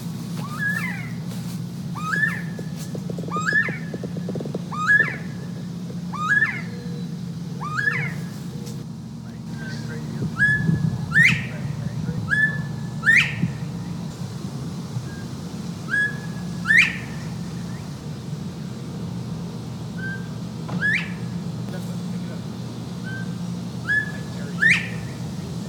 Back on this coast, while I was burning mean (too literally) on the Fourth of July BBQ, there was a bird call that I’ve never, EVER heard before. And it was *LOUD* and close, up in those trees that I showed you at the back edge of the hill.
I’ve condensed about five or six minutes of sound into just the parts where it was calling, about every 30-45 seconds.
Shall we try again, maybe focus on that second set of calls that seem different from the first one?